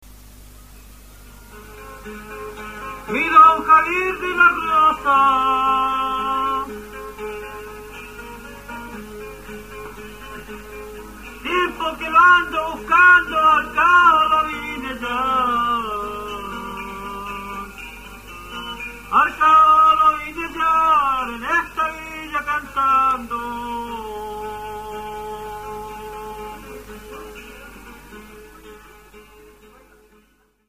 Cassette sonoro
El guitarrón: entrevista